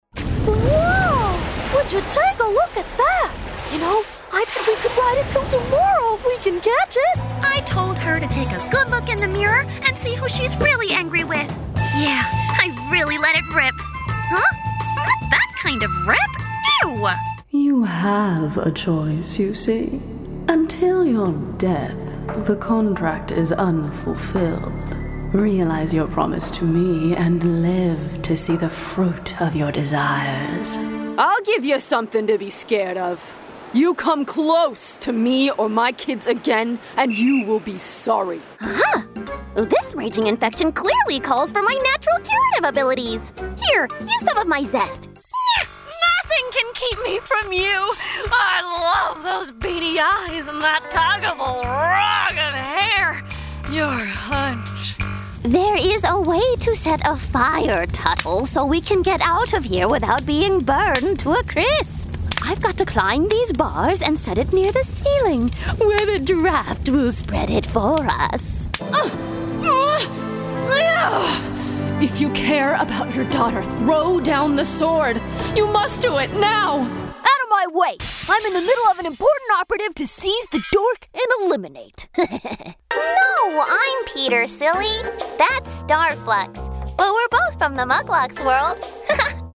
ANIMATION DEMO: